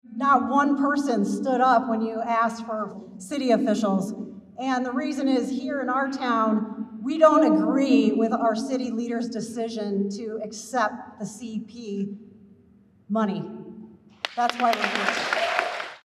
Nationwide — A company that owns railway in Sheldon wants to acquire Kansas City Southern. A federal board that regulates train acquisitions heard from Iowans for two hours on the night  of September 6th, speaking out against the acquisition.